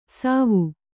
♪ sāvu